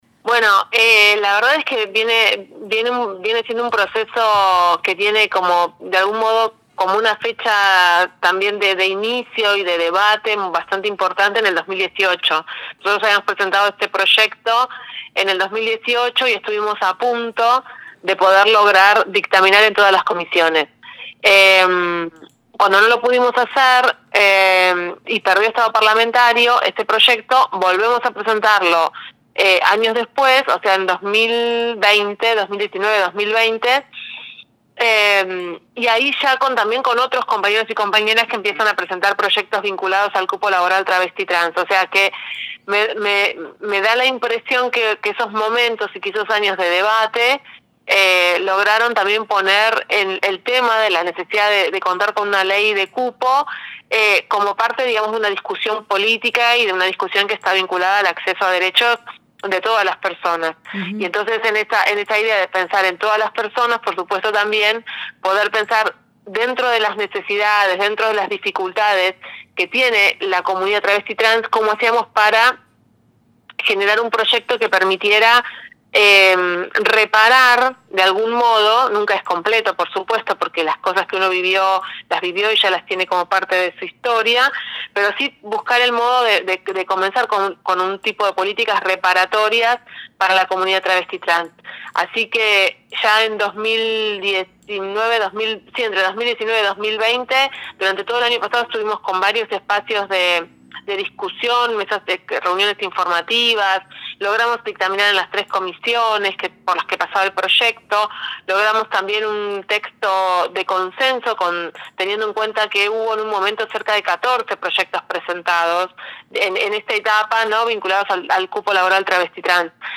Mónica Macha, Diputada Nacional del Frente de Todos por la provincia de Buenos Aires, fue una de las impulsoras de la Ley de Promoción al Acceso al Empleo Formal para Personas Travestis, Transexuales y Transgénero que se aprobó la semana pasada en el Congreso. En diálogo con Nosotres les Otres habló sobre cómo fue el proceso político que convirtió una demanda histórica de la comunidad trans en Ley.